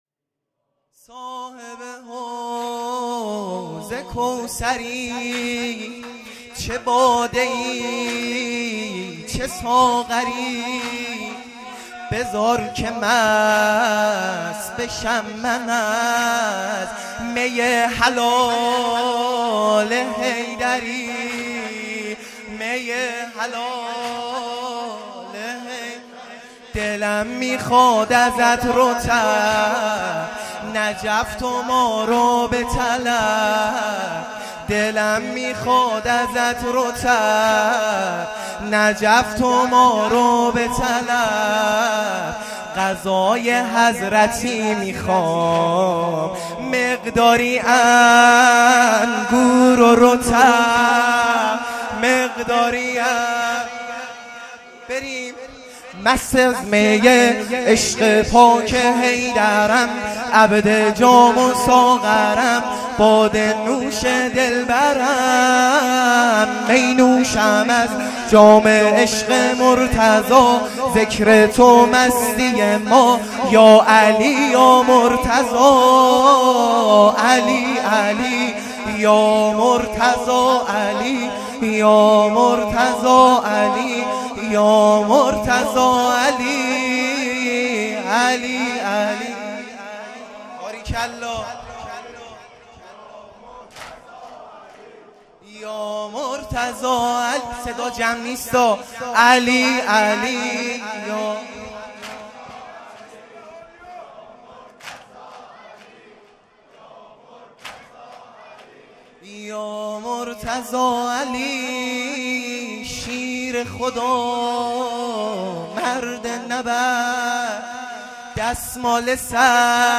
واحد | صاحب حوض کوثری